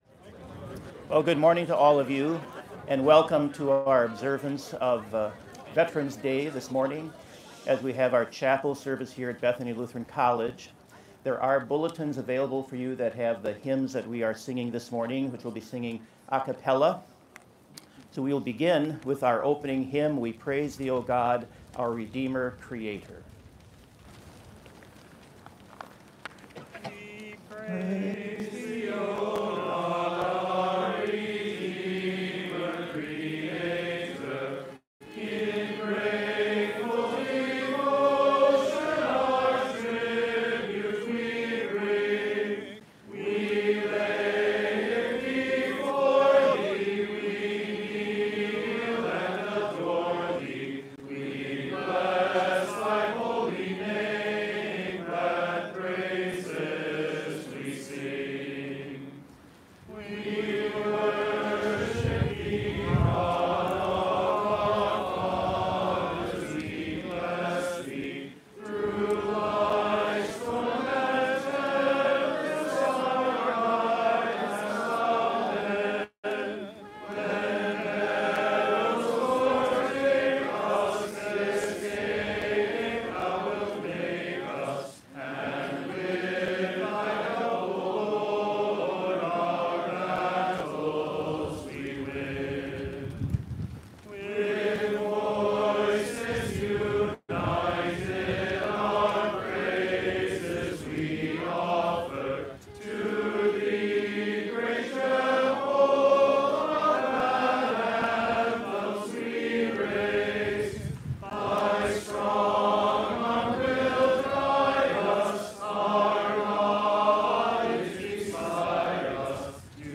Complete service audio for Chapel - Monday, November 11, 2024
Prelude